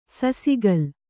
♪ sasigal